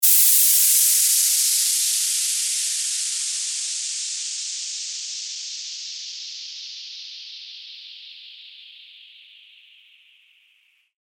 FX-718-WHOOSH
FX-718-WHOOSH.mp3